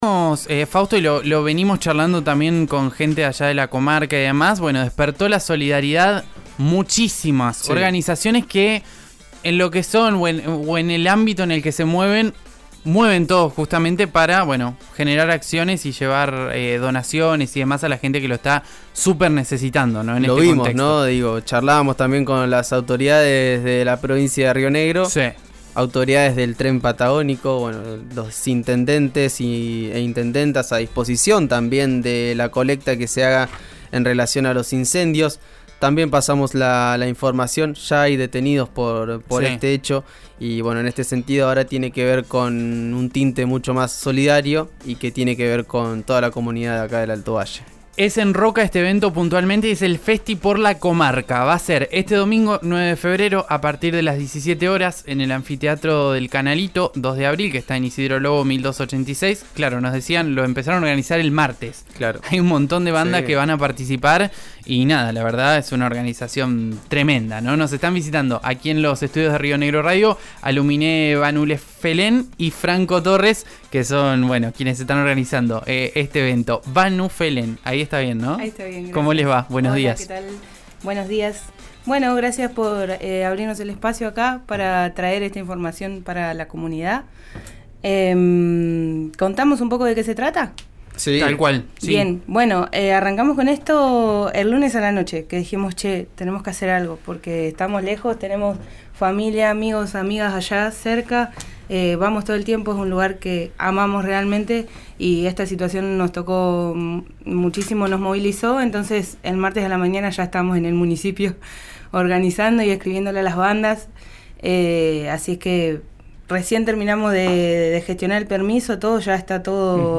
organizadores del evento en diálogo con «Pará un Poco» (Lun a vie de 11 a 13 por RÍO NEGRO RADIO).